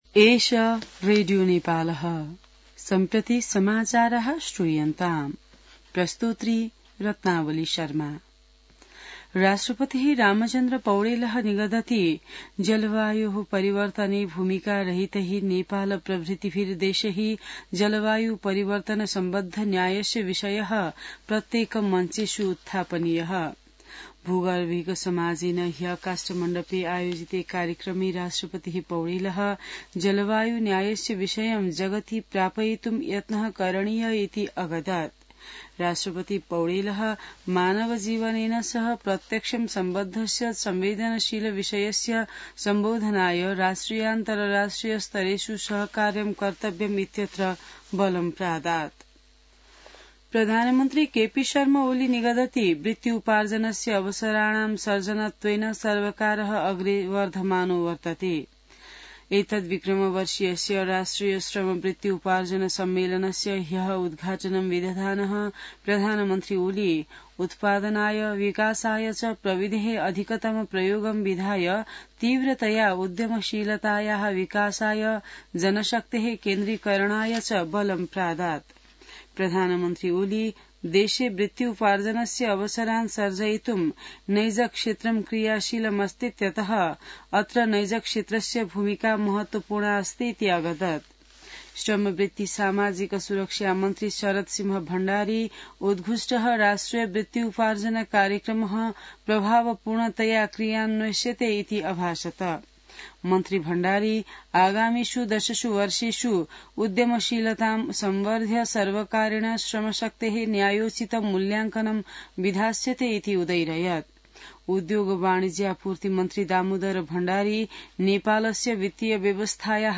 संस्कृत समाचार : २८ फागुन , २०८१